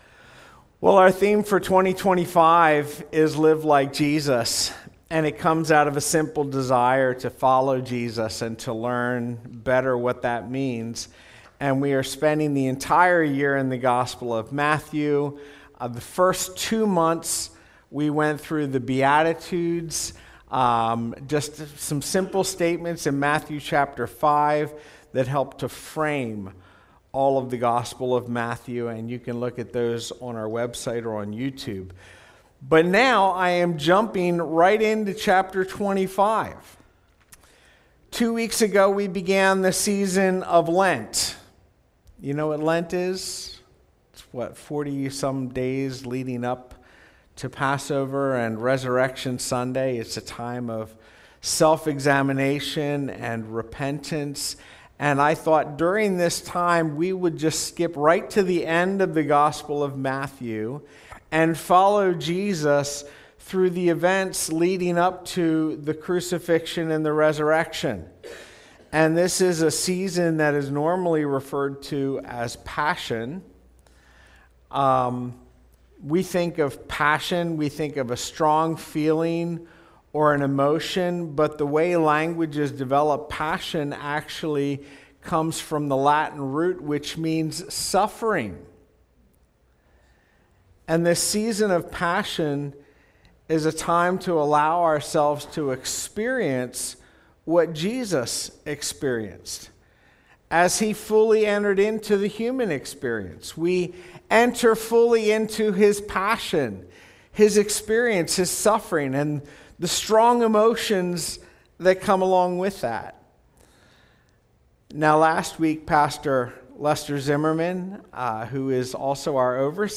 Sermons | Spring City Fellowship